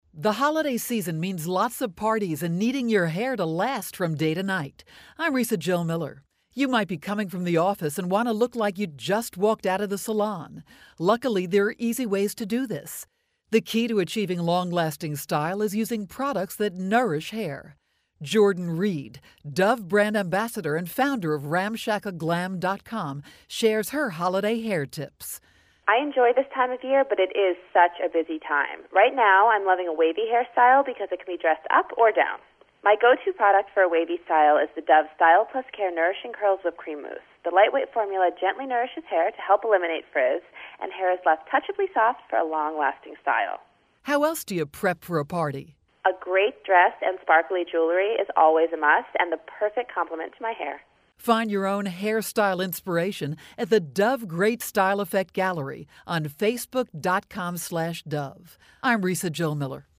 November 27, 2012Posted in: Audio News Release